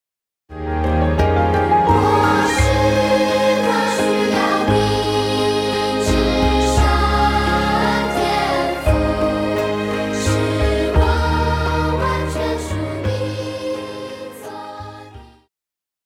Christian
Pop chorus,Children Voice
Band
Hymn,POP,Christian Music
Voice with accompaniment
為了淺顯易懂，除了把現代樂風融入傳統聖詩旋律，針對部份艱澀難懂的歌詞，也稍作修飾，儘可能現代化、口語化；